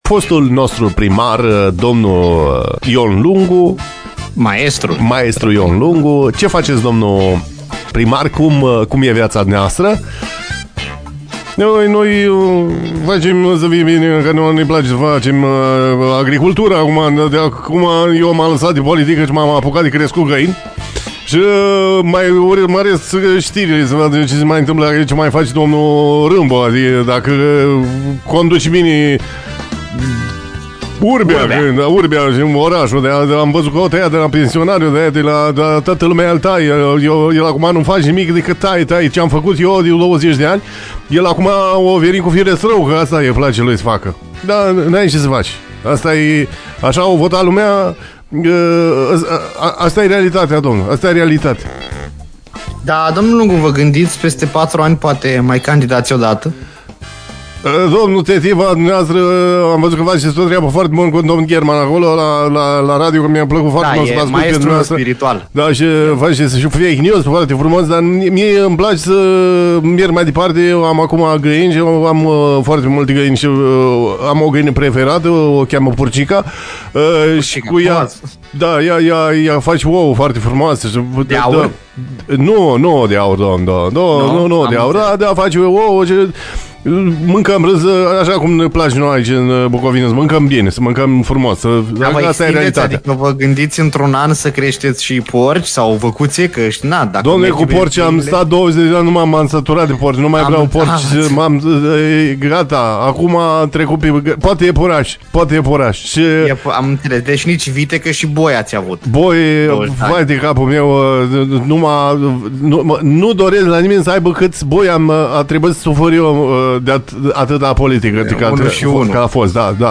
PARODIE / Ion Lungu la DIS DE DIMINEAȚĂ : ce face fără politică?